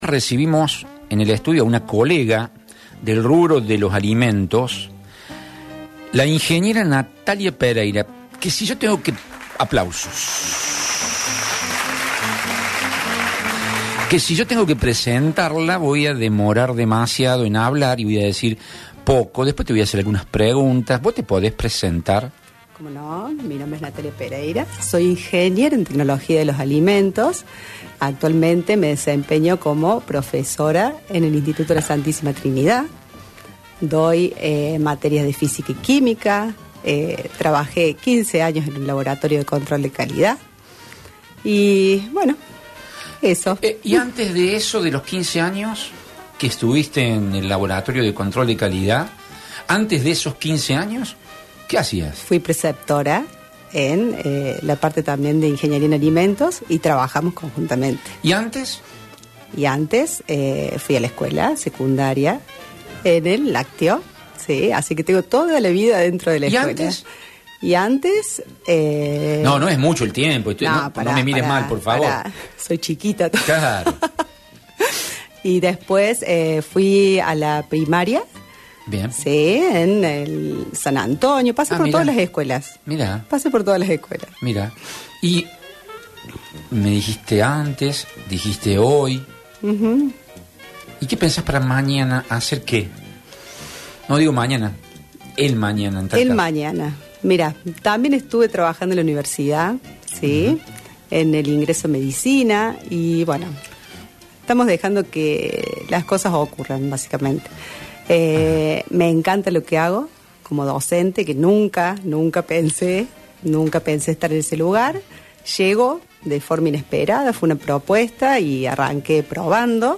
Zensorial: La importancia de la alimentación, nutrición y educación | Entrevista - grupo radial centro